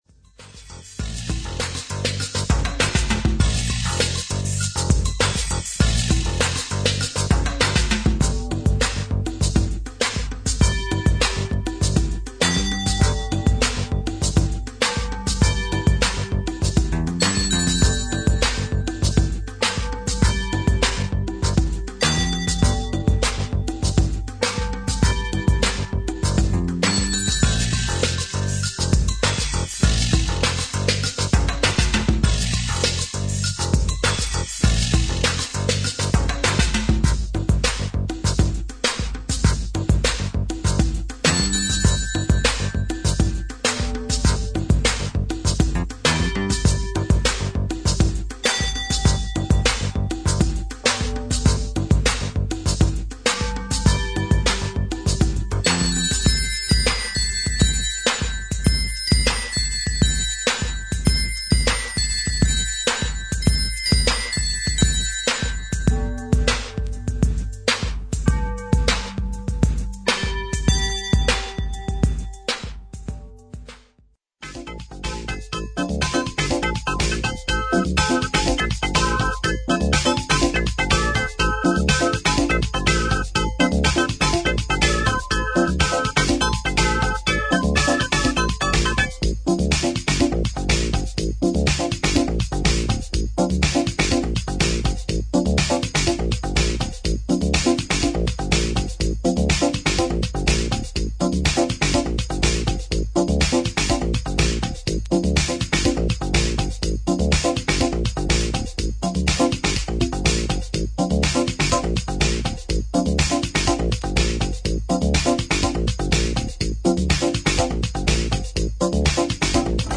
主にレトロで味のある質感のシンセやドラム・マシンで構築され
エレクトロニックながらも躍動感のある作品に仕上がっています。